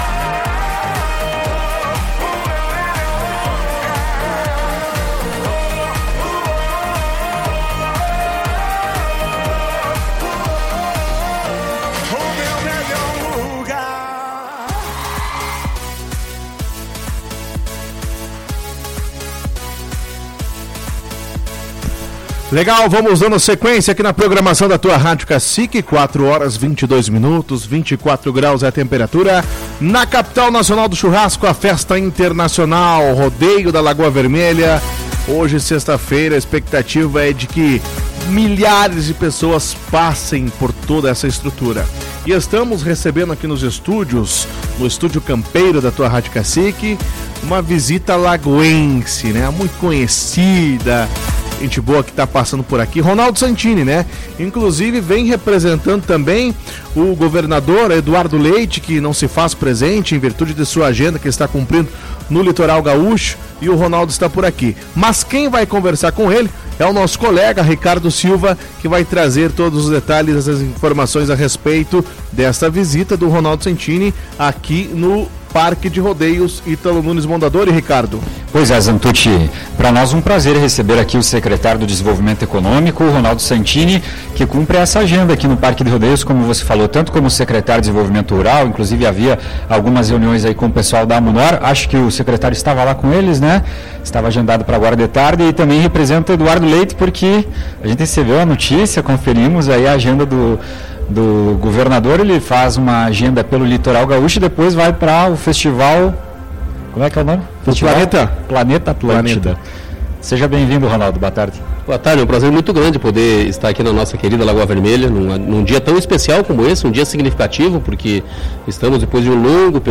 Em visita ao Estúdio Campeiro da Tua Rádio Cacique, nesta sexta- feira, 03 de fevereiro, o secretário do Desenvolvimento Rural do Estado do Rio Grande do Sul, Ronaldo Santini, tratou da agenda que cumpre na XXI Festa Nacional do Churrasco, VI Rodeio Internacional, XXV Rodeio Crioulo Internacional, X Mostra de Fotografia, Doces e Comida Campeira e I Agro Rodeio Sicredi.